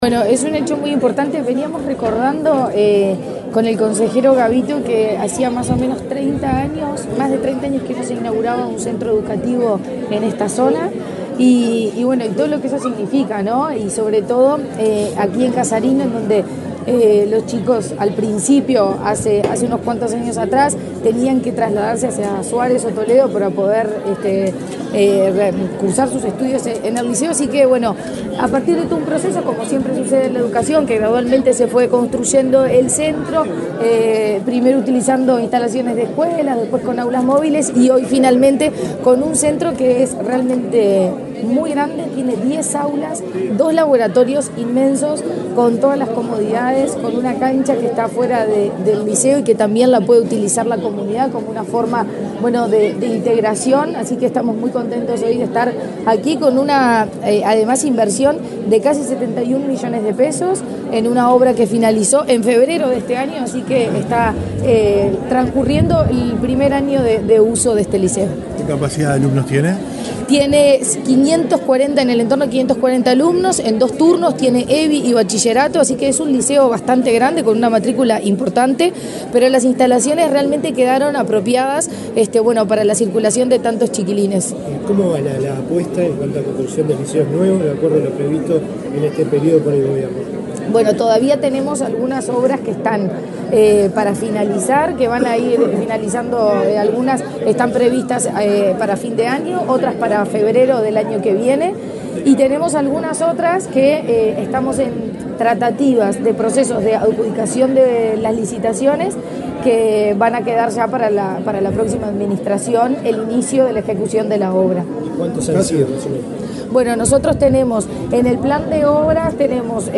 Declaraciones de la presidenta de la ANEP, Virginia Cáceres
La presidenta de la ANEP, Virginia Cáceres, dialogó con la prensa, antes de participar en la inauguración del edificio del liceo de Casarino, en el